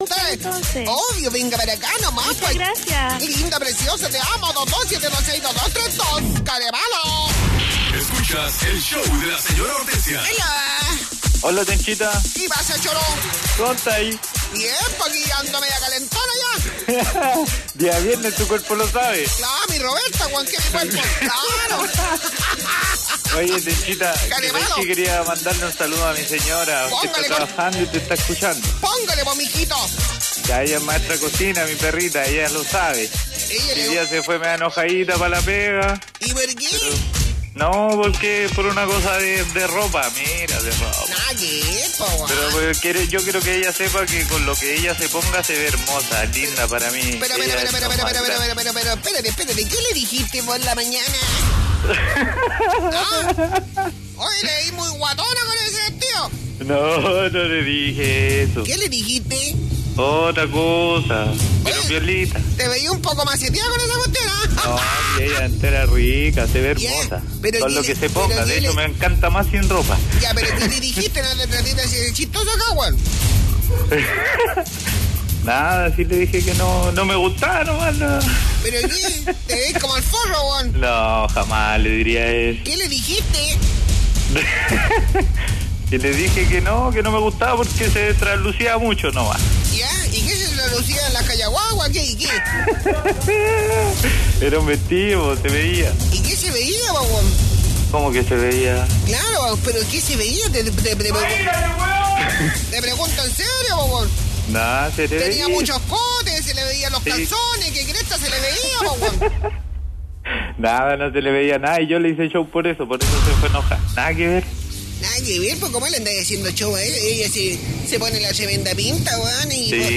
Un lolo llamó para declararle su amor a una amiga